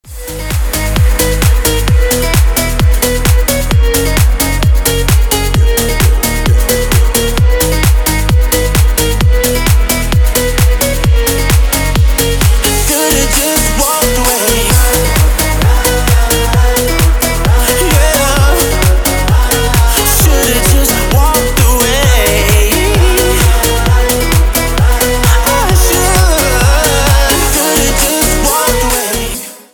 Категория: Dance рингтоны